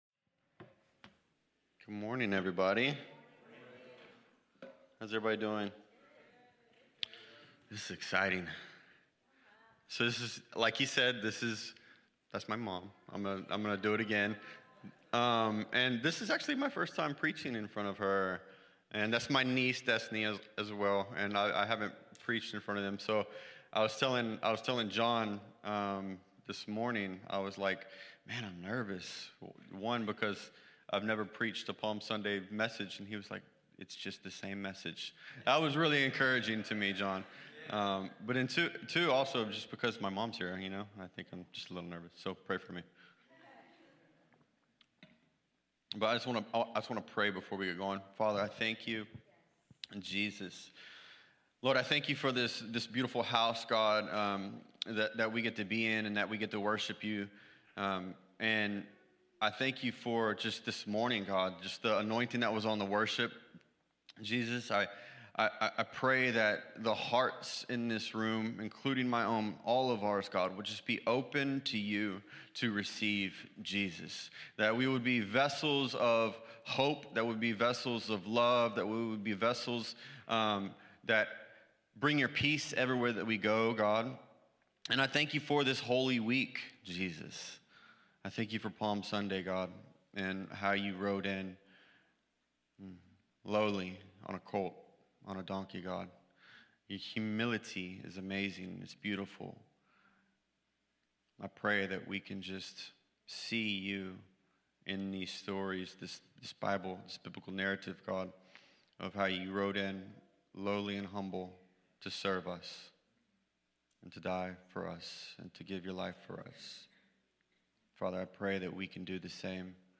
Cornerstone Fellowship Sunday morning service, livestreamed from Wormleysburg, PA.